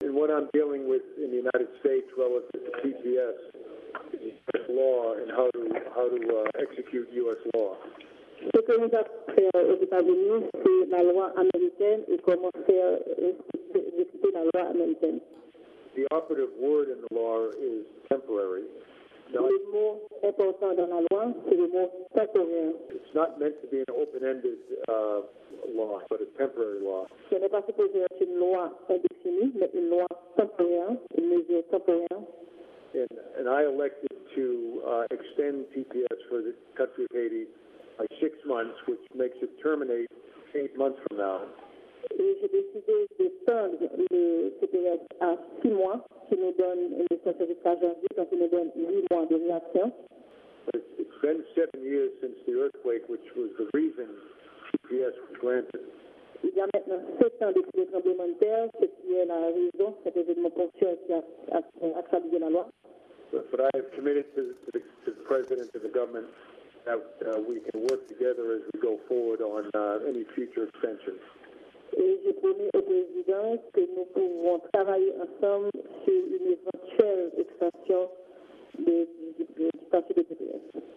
Dekalrasyon Minis Sekirite Teritoryal Etazini an John Kelly.